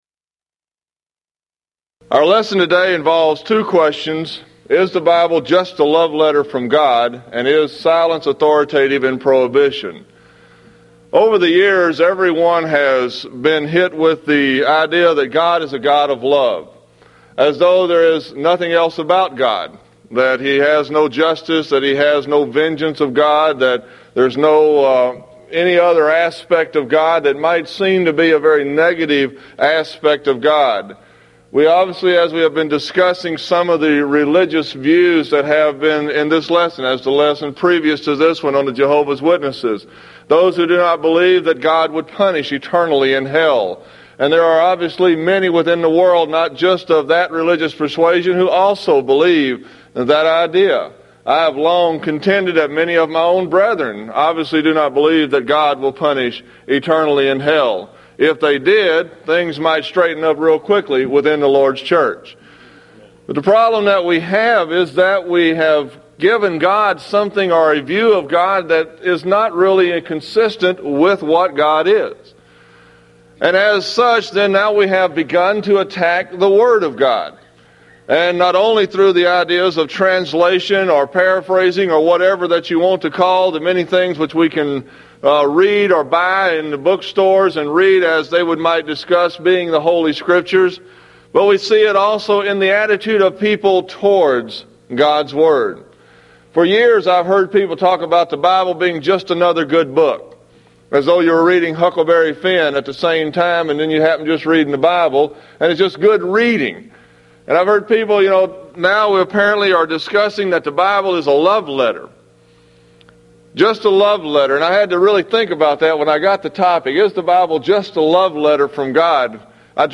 Event: 1995 Mid-West Lectures Theme/Title: The Twisted Scriptures
If you would like to order audio or video copies of this lecture, please contact our office and reference asset: 1995Midwest20